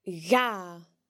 If dh is used before a broad vowel (a, o, or u), it is pronounced similarly to the English ‘g’, as can be heard in dhà (the dependent form of , two):